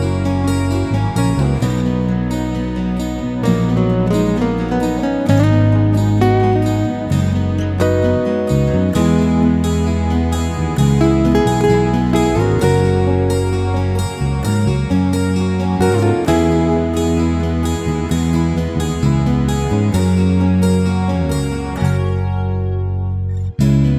Duet Version Pop